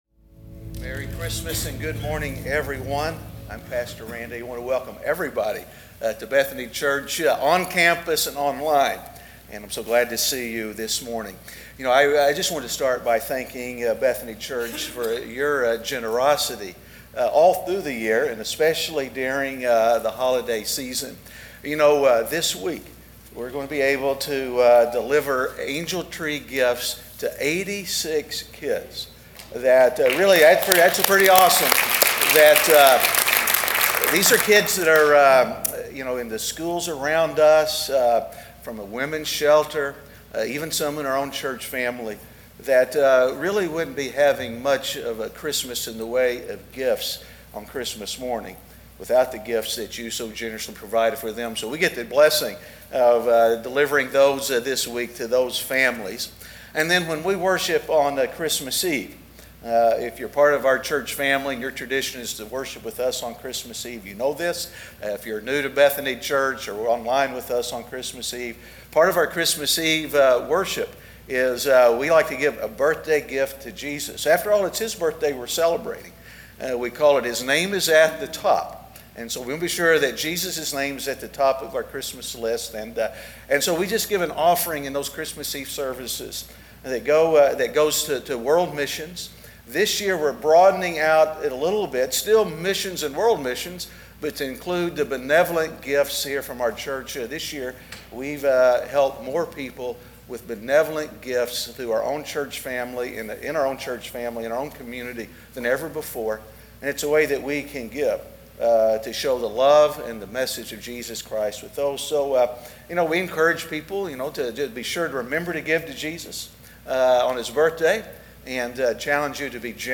Advent He Keeps His Promises (Week 3) - Sermon.mp3